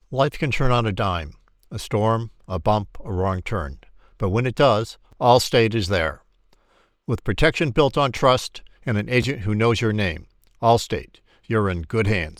Allstate narrator
North American English. Voice style: Mature, articulate, genuine, trustworthy, experienced, and engaging.
Middle Aged
Senior
Commercial